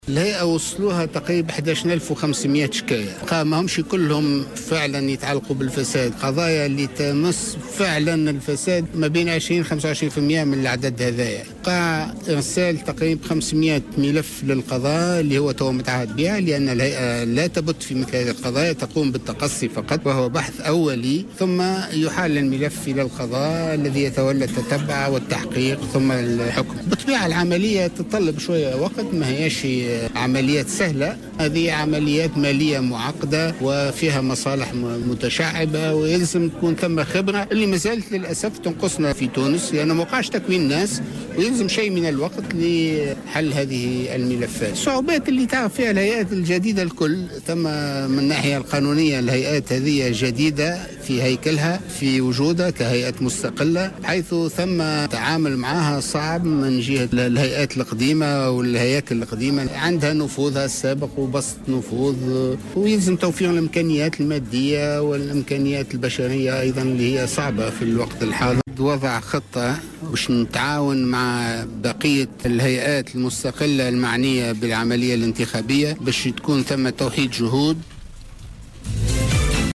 قال رئيس الهيئة الوطنية لمكافحة الفساد، سمير العنابي على هامش حضوره في ندوة حول" الهيئات المستقلة والمجتمع المدني والاستحقاقات الإنتخابية" انتظمت اليوم،السبت بمعهد الصحافة وعلوم الاخبار أنه تم إحالة 500 ملف فساد على القضاء.